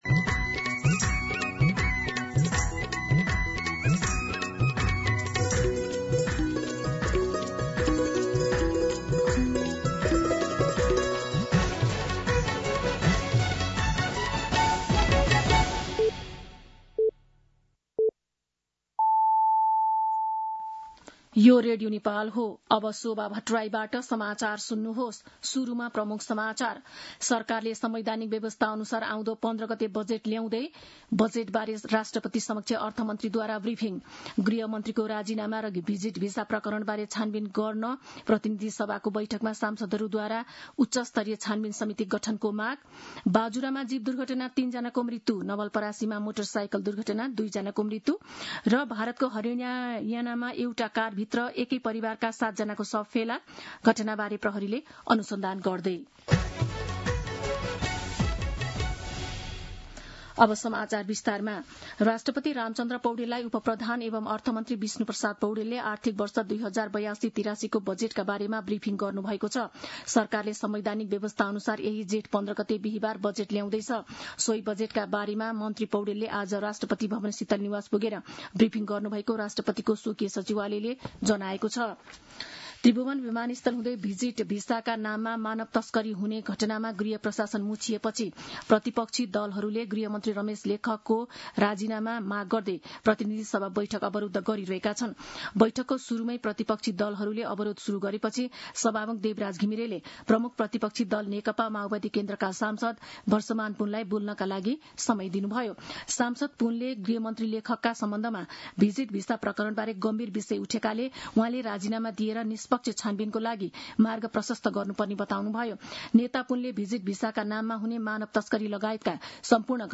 दिउँसो ३ बजेको नेपाली समाचार : १३ जेठ , २०८२
3pm-News-13.mp3